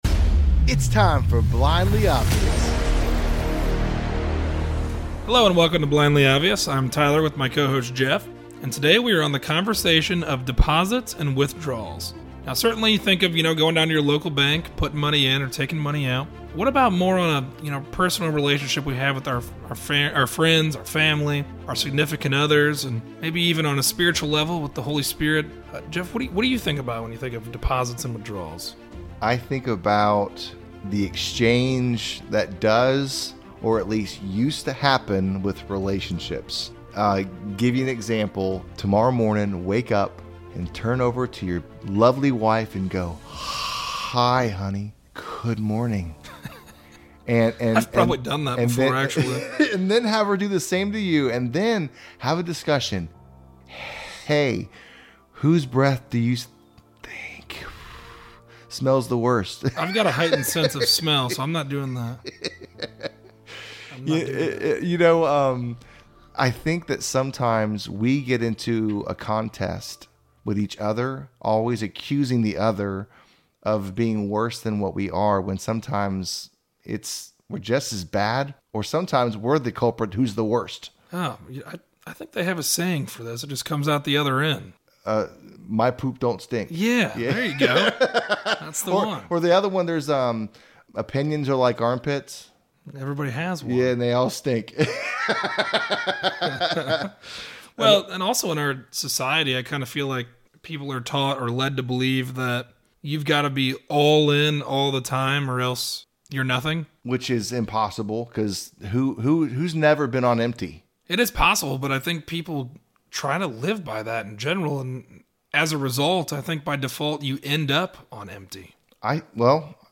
A conversation on deposits and withdrawals. We of course all understand this meaning in terms of our bank accounts, but what about on a level of our relationships?